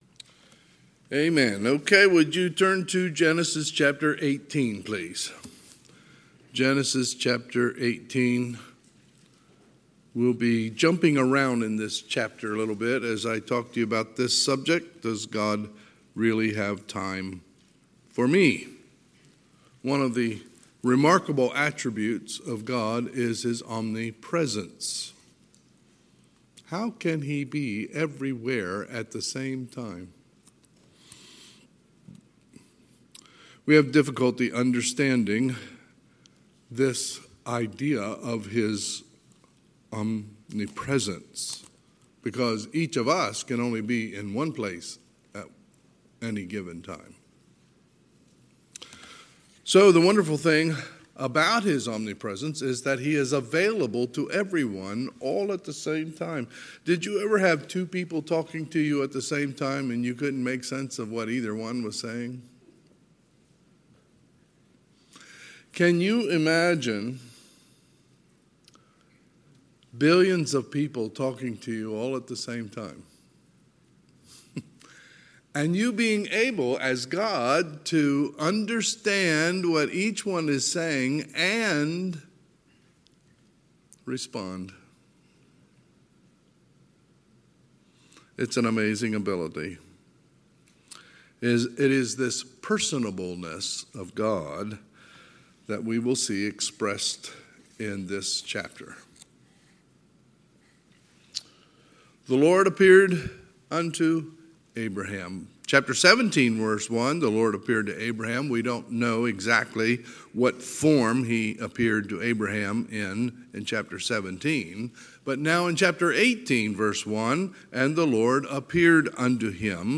Sunday, July 17, 2022 – Sunday PM